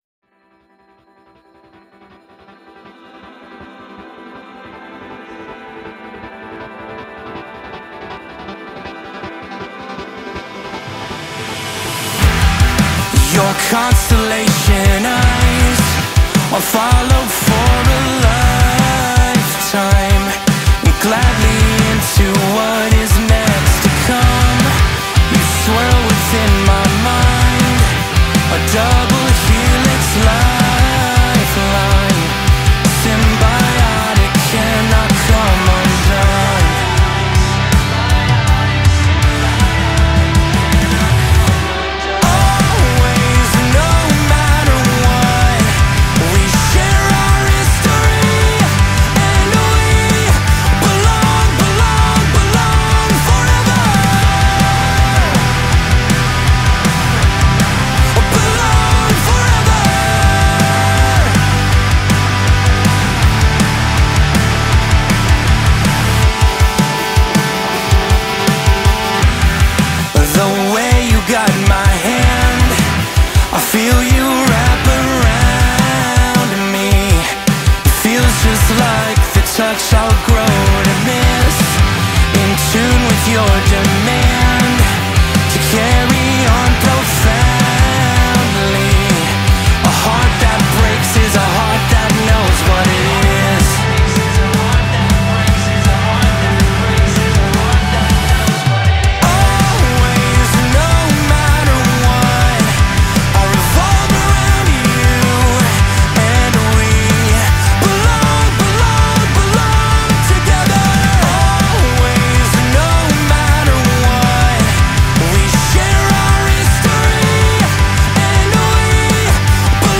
leans into emotional gravity without turning into melodrama
Sometimes it is steady.